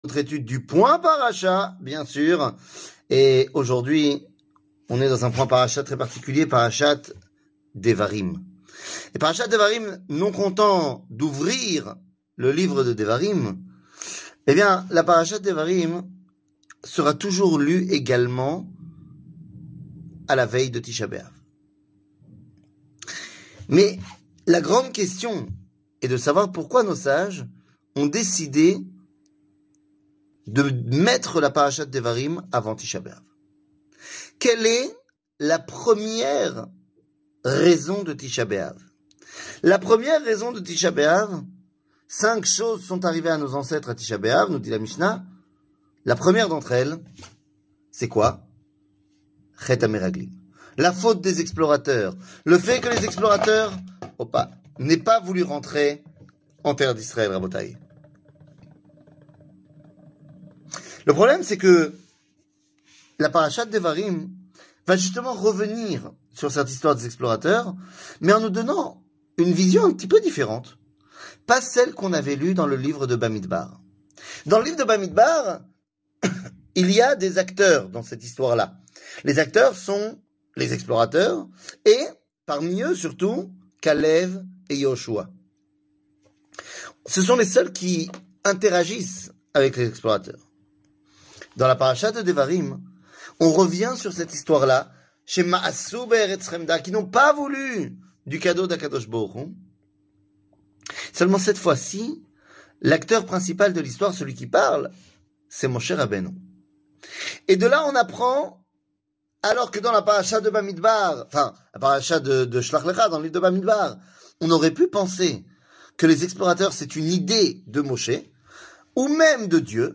Le point Paracha, Devarim, La vrai raison 00:05:09 Le point Paracha, Devarim, La vrai raison שיעור מ 20 יולי 2023 05MIN הורדה בקובץ אודיו MP3 (4.71 Mo) הורדה בקובץ וידאו MP4 (6.76 Mo) TAGS : שיעורים קצרים